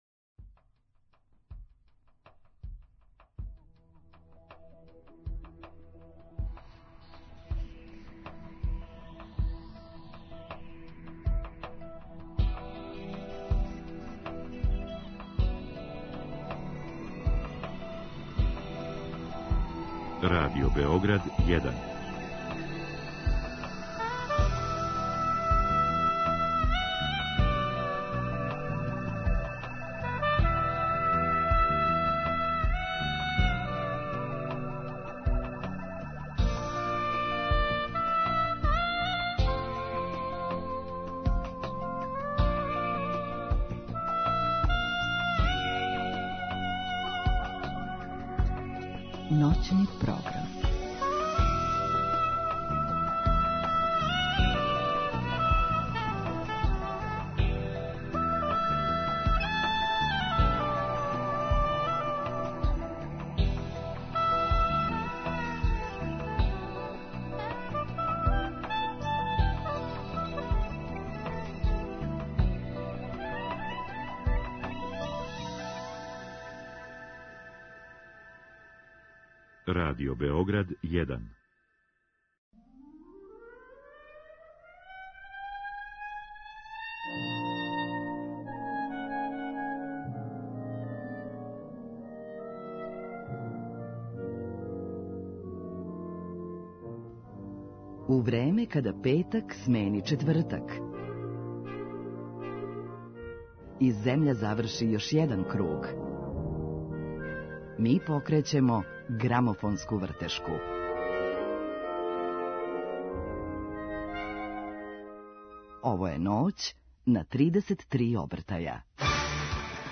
Гост Владимир Ђурић Ђура.